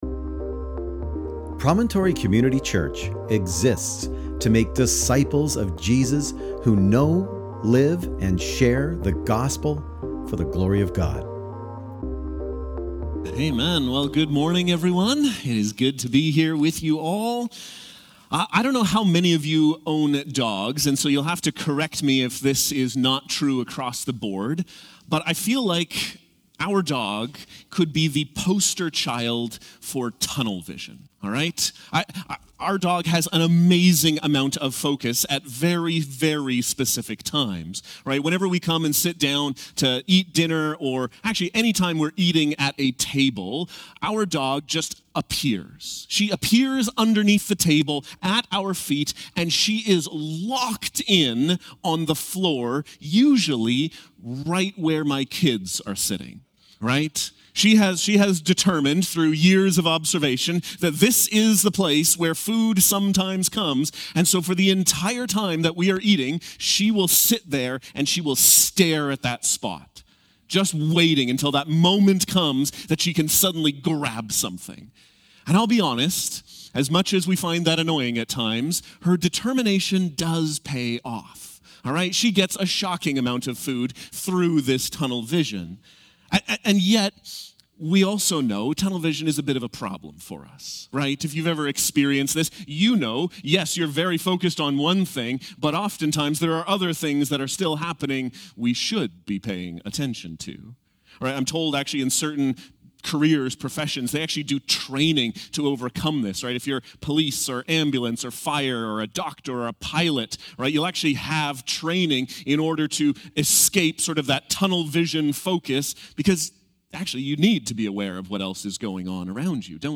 Sermon Transcript: (transcribed with AI) Hey, good morning everyone.